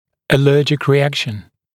[əˈləːdʒɪk rɪ’ækʃn][эˈлё:джик ри’экшн]аллергическая реакция